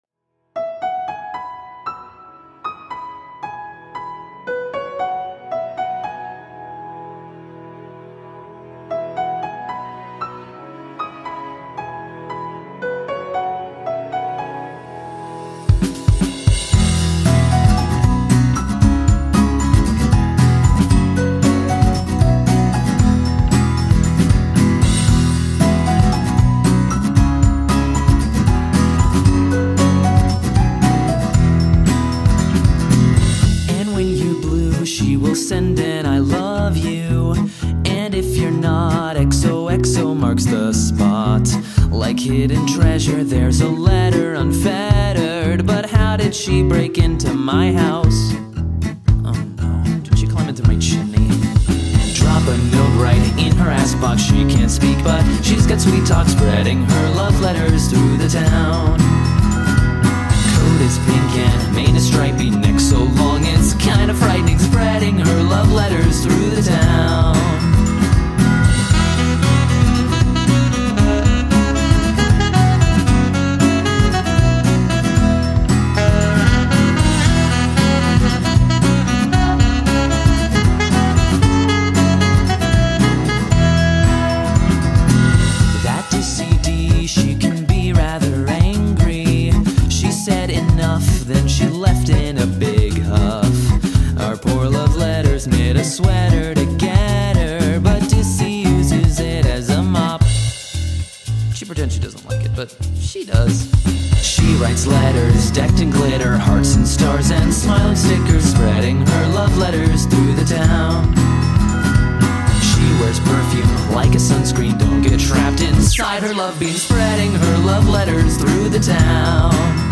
Vocals
Saxophone